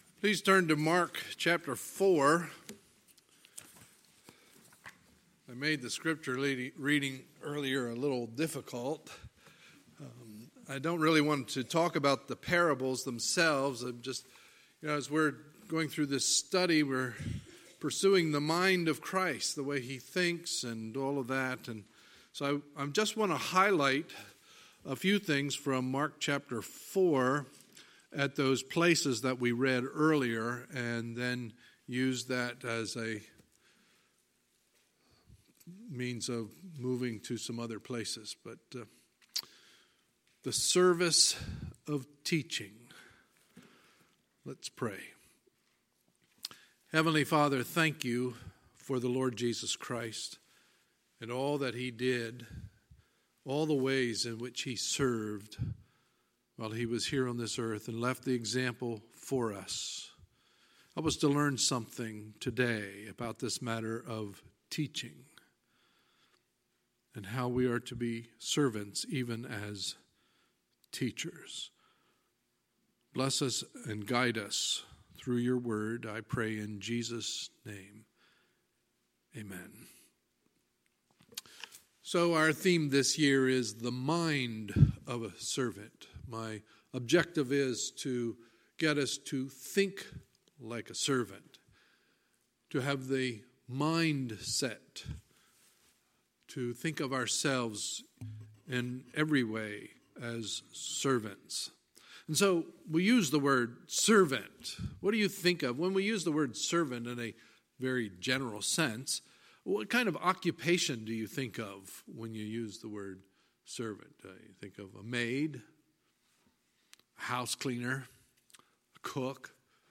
Sunday, March 31, 2019 – Sunday Morning Service